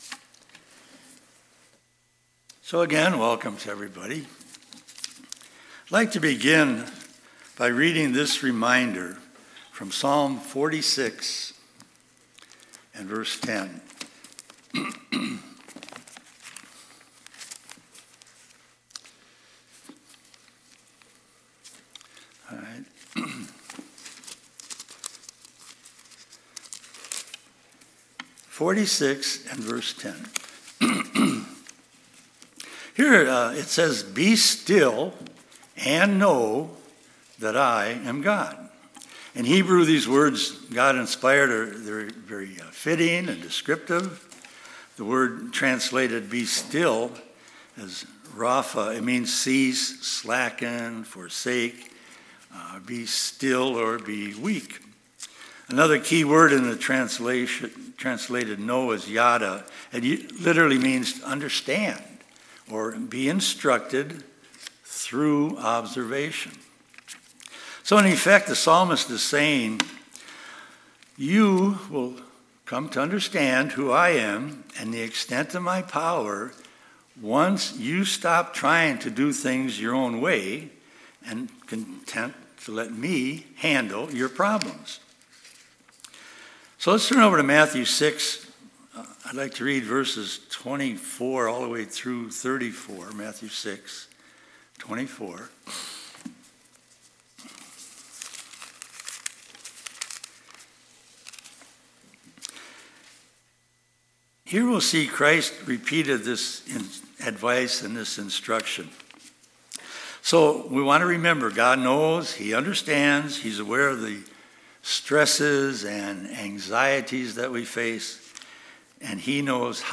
Given in Twin Cities, MN